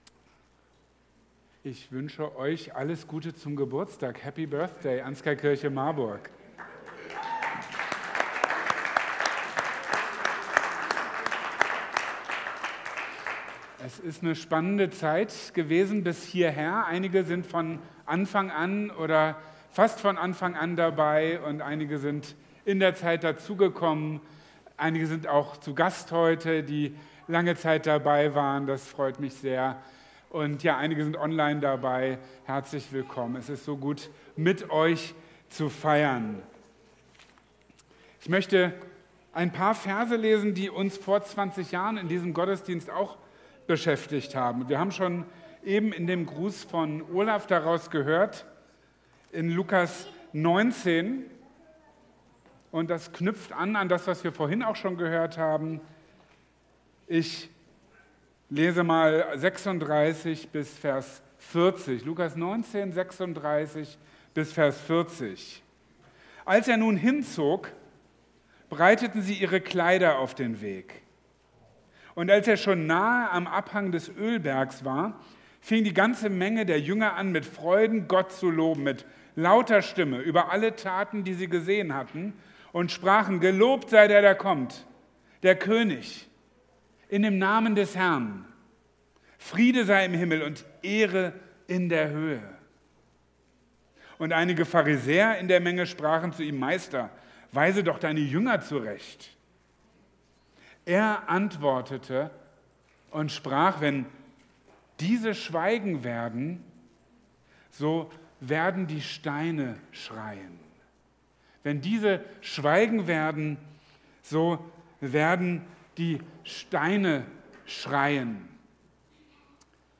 Wenn diese schweigen werden, so werden die Steine schreien | Marburger Predigten
Am Palmsonntag 2002 wurde unsere Gemeinde gegründet – an diesem Palmsonntag feiern wir 20 Jahre Anskar-Kirche Marburg!